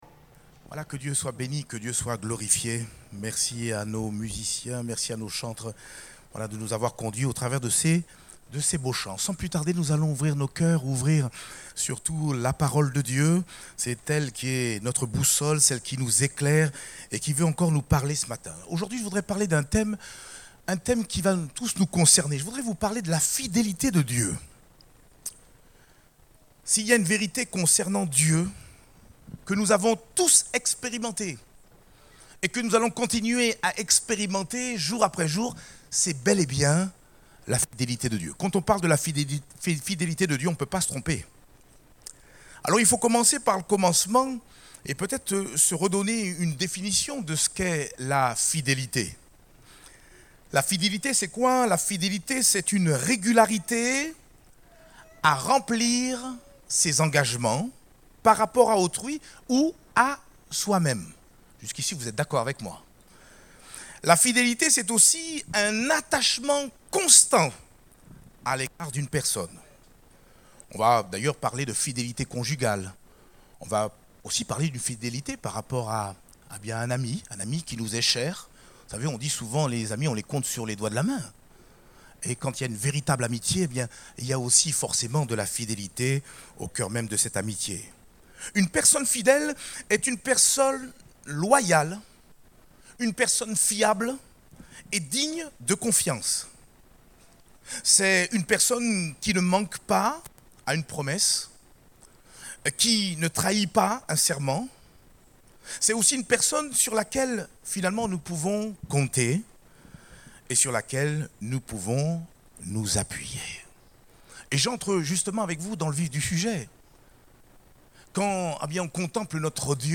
Date : 23 avril 2023 (Culte Dominical)